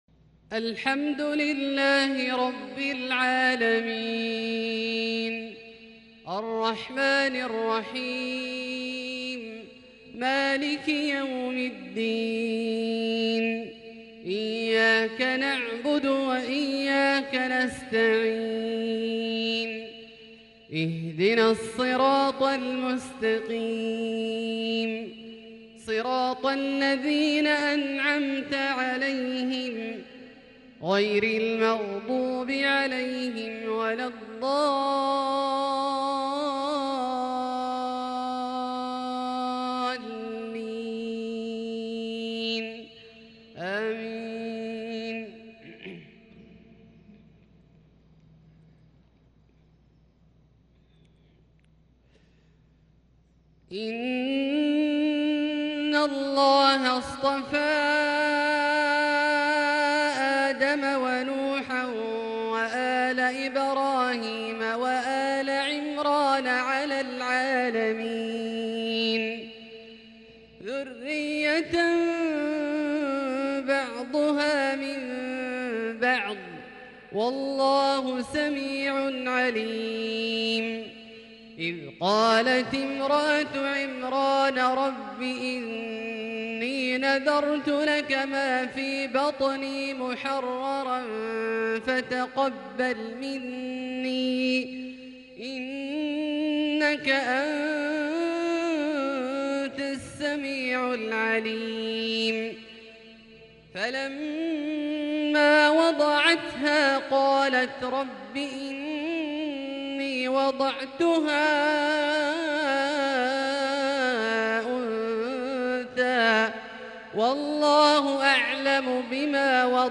صلاة العشاء ٧-٣-١٤٤٢ من سورة آل عمران (33-51) > ١٤٤٢ هـ > الفروض - تلاوات عبدالله الجهني